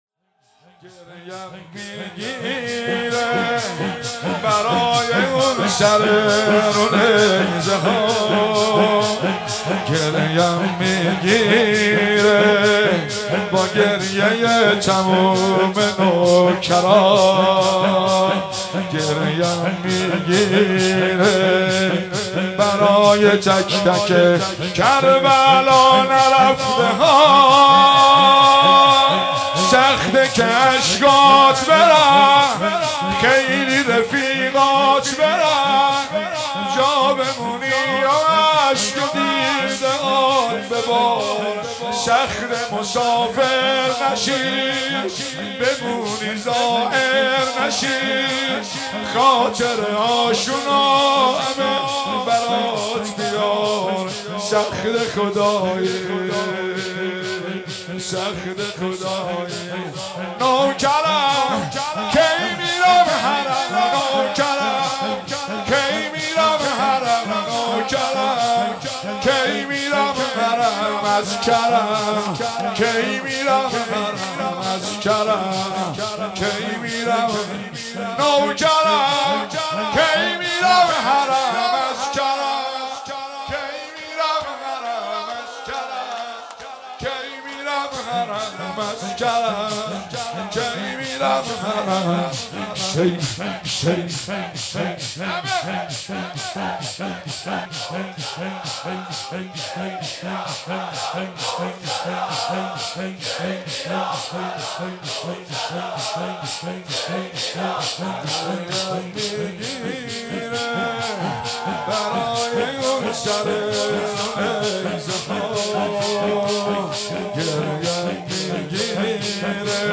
شب دوم محرم 96 - هیئت فاطمیون - شور - گریه ام میگیره برای اون سر رو نیزه ها